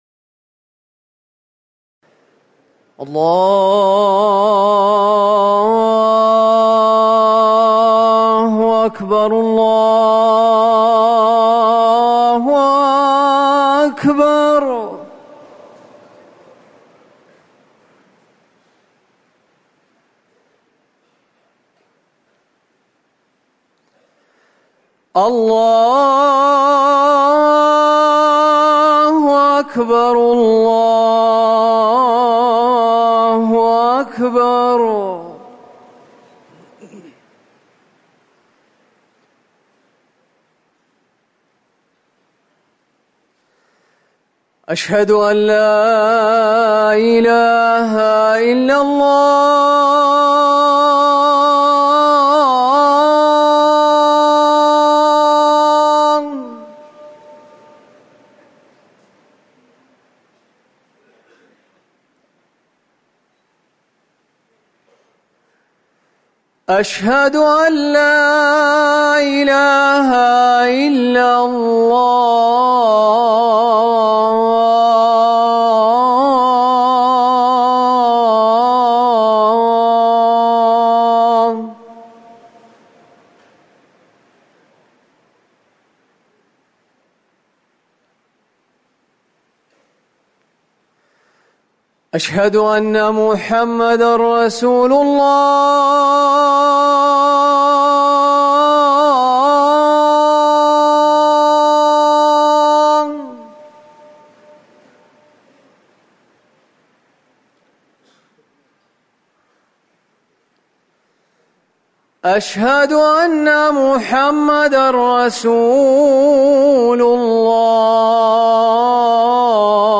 أذان الظهر
تاريخ النشر ١٦ صفر ١٤٤١ هـ المكان: المسجد النبوي الشيخ